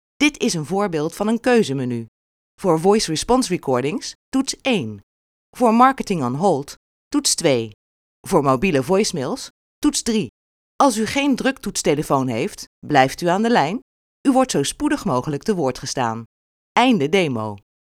Beluister hier enkele voorbeelden van onze professionele voice over stemartiesten.